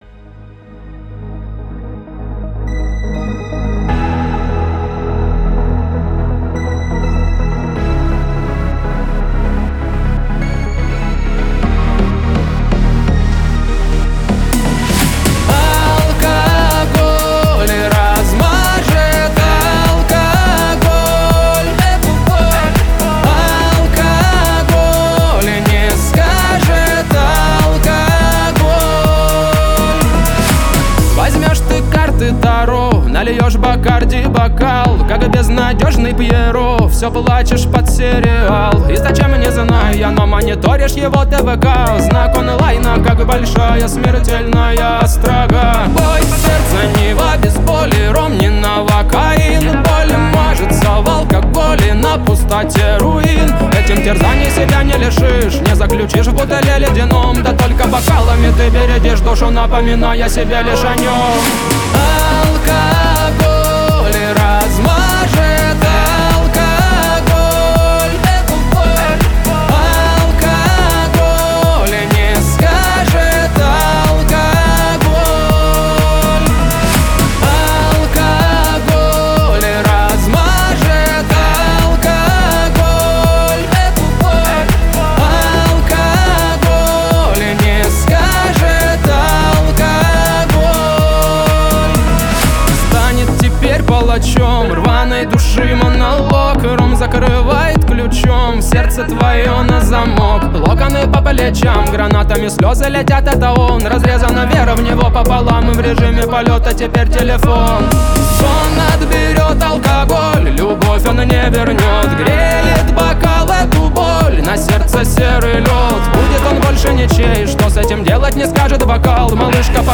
Жанр: Узбекские треки